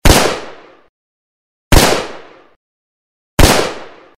Несколько выстрелов из оружия